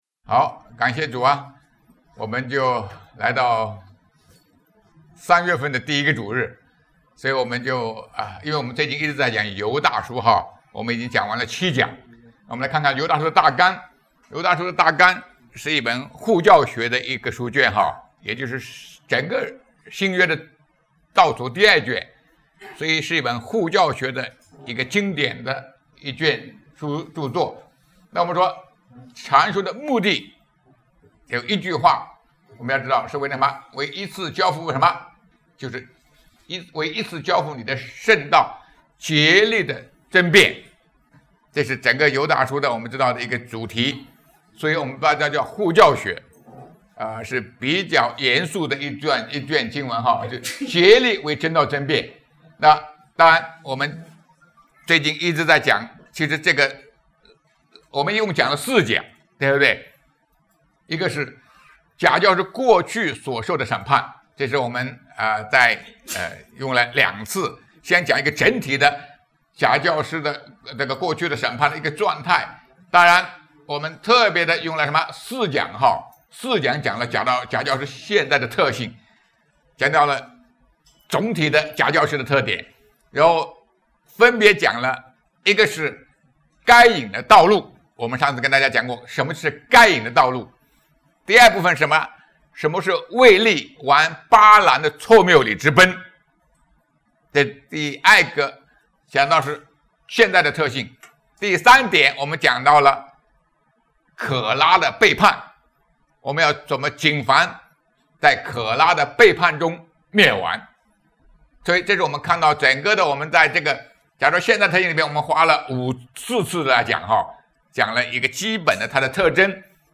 《犹大书》第八讲 假教师未来的审判! 2025年3月2日 下午5:33 作者：admin 分类： 犹大书圣经讲道 阅读(1.17K